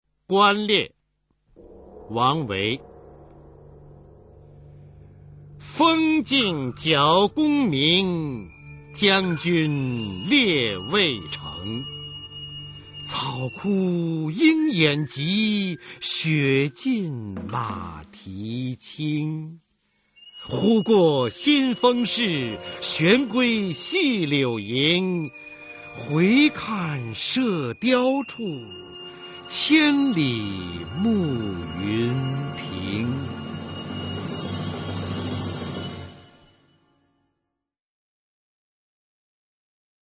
[隋唐诗词诵读]王昌龄-观猎 配乐诗朗诵